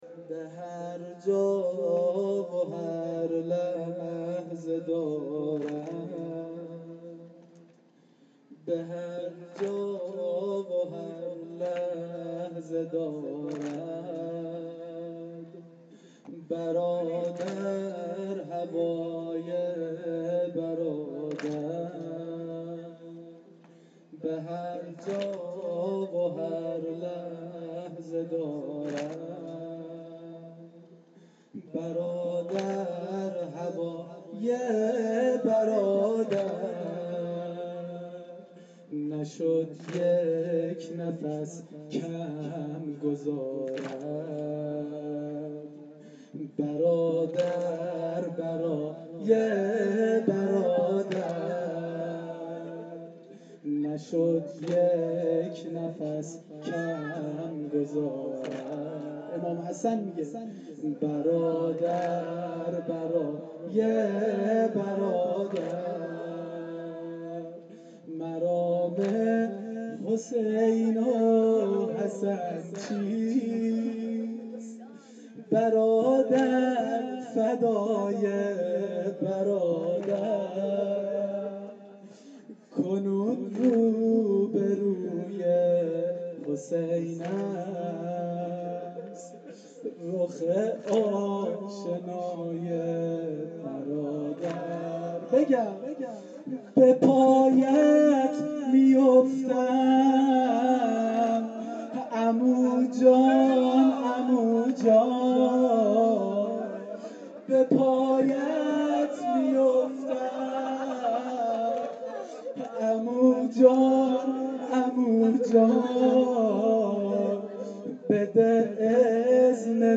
واحد سنگین شب پنجم محرم1393